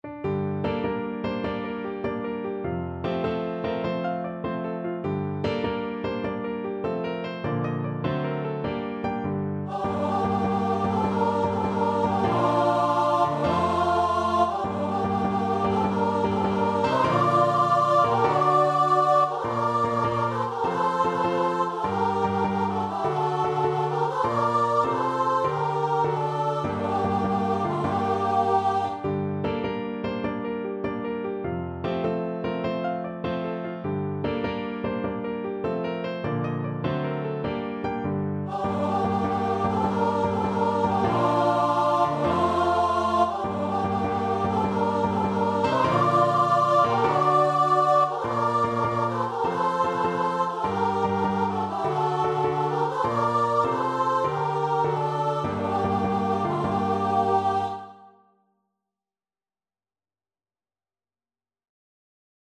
6/8 (View more 6/8 Music)
.=100 With spirit
Vocal Duet  (View more Easy Vocal Duet Music)